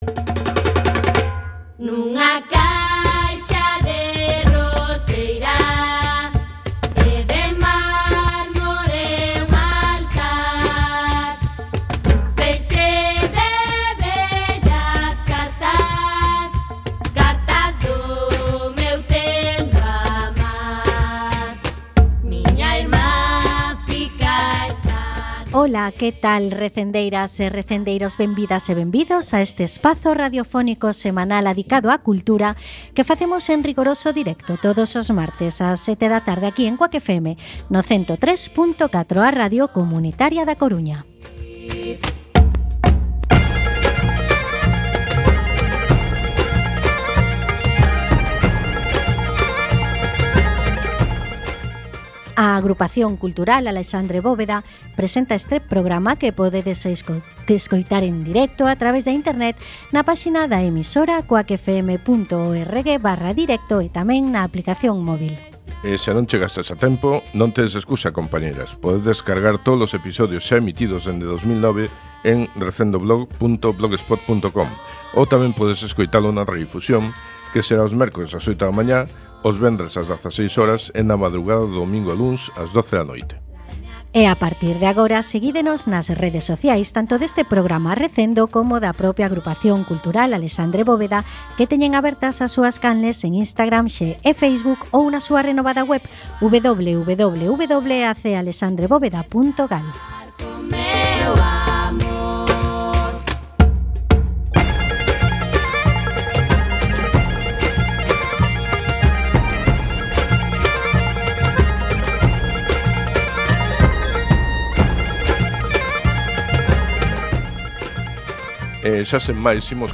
Programa número 514, no que entrevistamos a Ricardo Cao, Reitor da Universidade da Coruña.